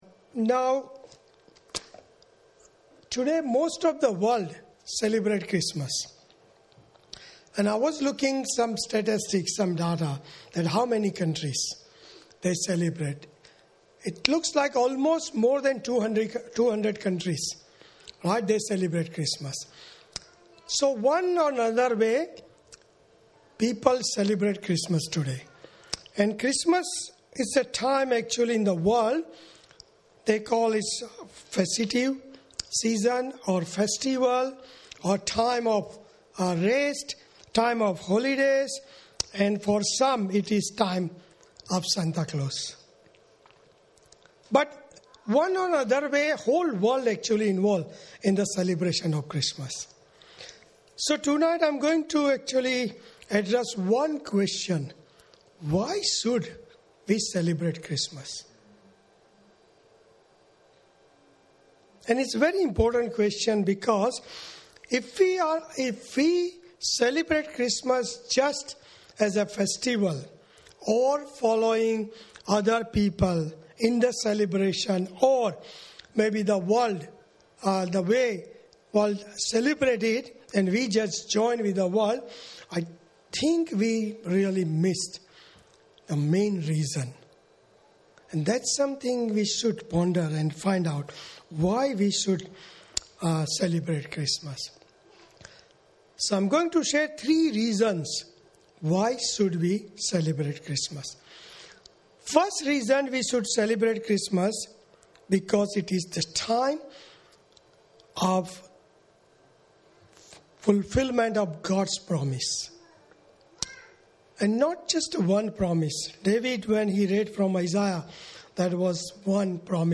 Outreach Service - Message - St. Andrew's Presbyterian Church Clayton